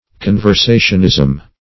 Search Result for " conversationism" : The Collaborative International Dictionary of English v.0.48: Conversationism \Con`ver*sa"tion*ism\ (-[i^]z'm), n. A word or phrase used in conversation; a colloquialism.